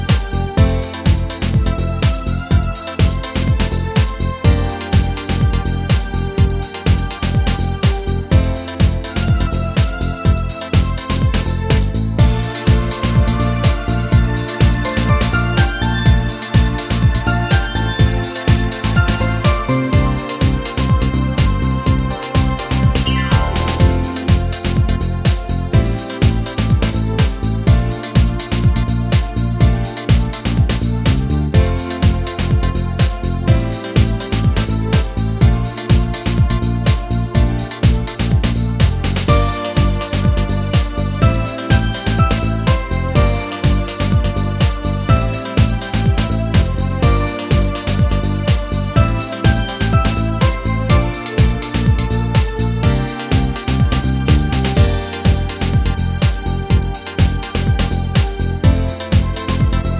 no Backing Vocals Soul / Motown 3:30 Buy £1.50